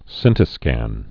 (sĭntĭ-skăn)